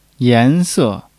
yan2--se4.mp3